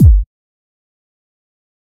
harddrop.ogg